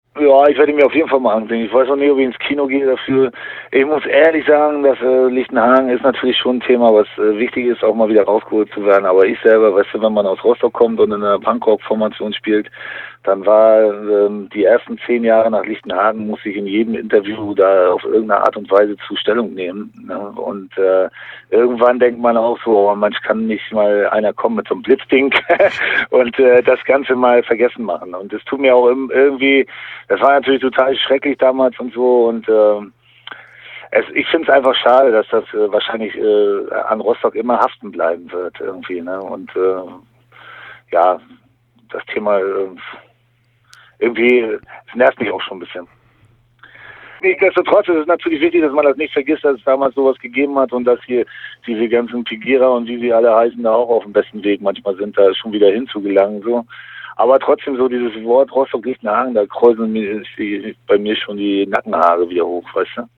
LOHRO im Gespräch mit „Dritte Wahl“